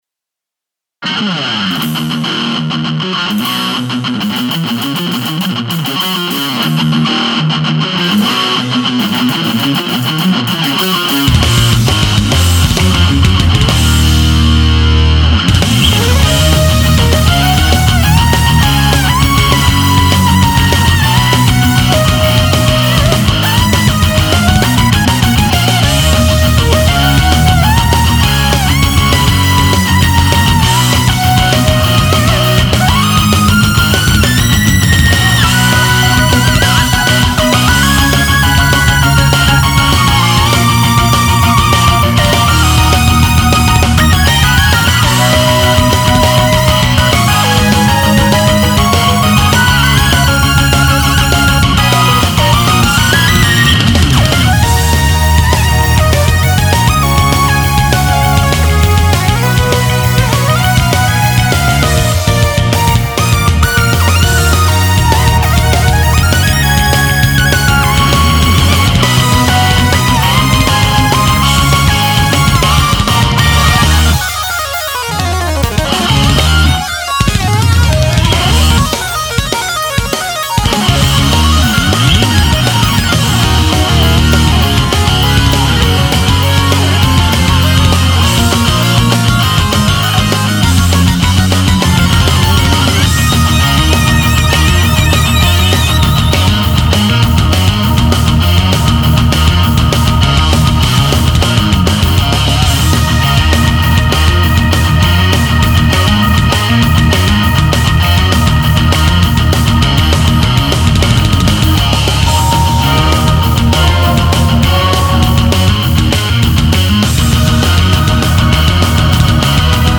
シンセサウンドも織り混ぜたハードロック曲です。